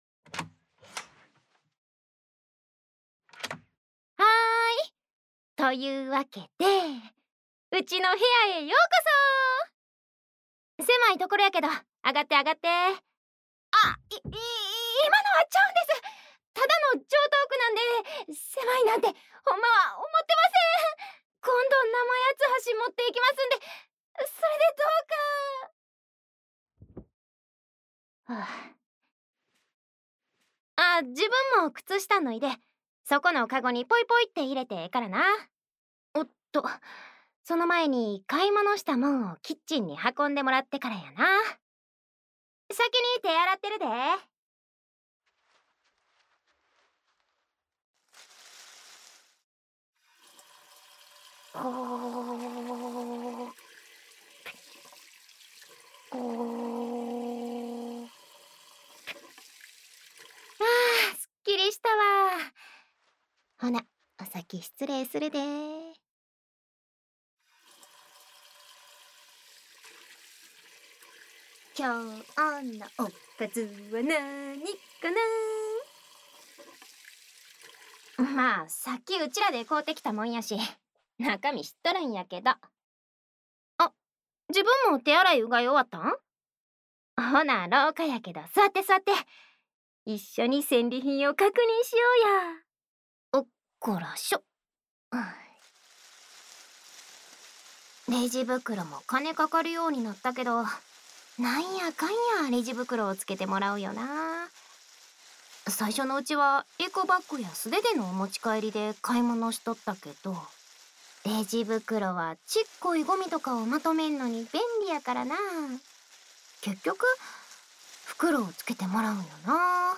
日常/生活 治愈 关西腔 掏耳 环绕音 ASMR 低语